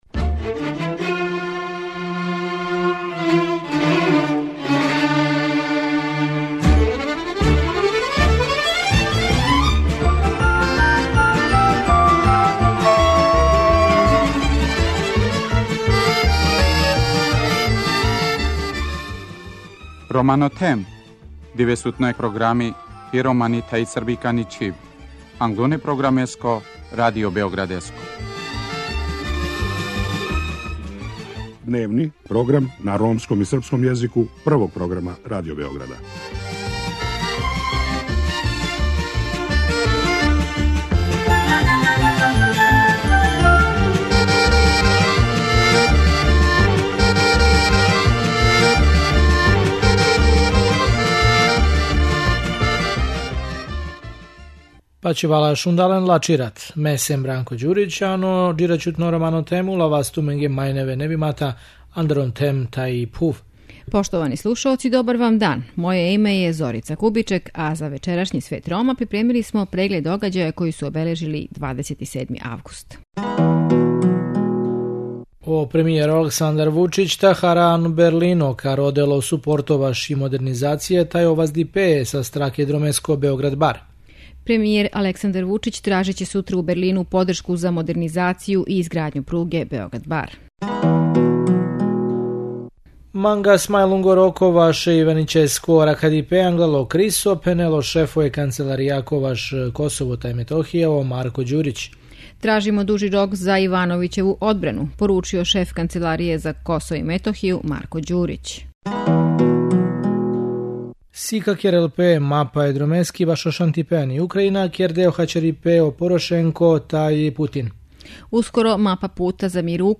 Радио Београд 1, 19.20
преузми : 19.11 MB Romano Them Autor: Ромска редакција Емисија свакодневно доноси најважније вести из земље и света на ромском и српском језику.